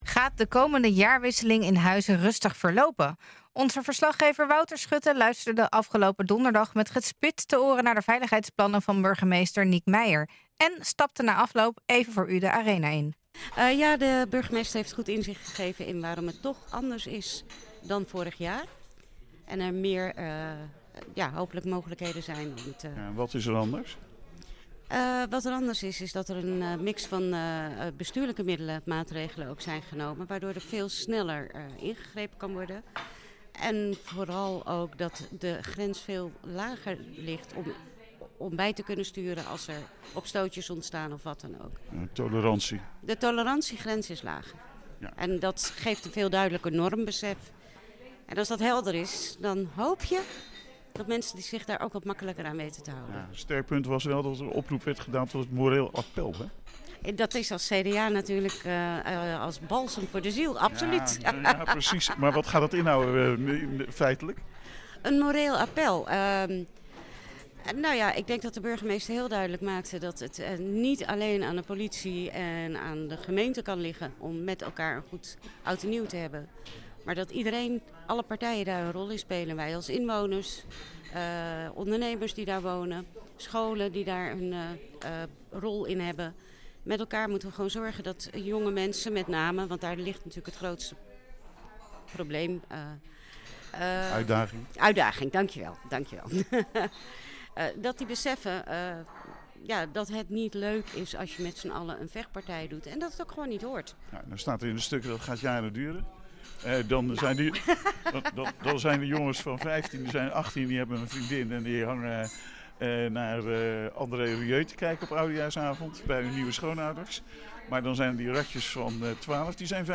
En stapte na afloop even voor u de arena in en sprak met mevrouw Vos van het CDA.